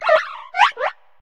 Cri de Pohmotte dans Pokémon HOME.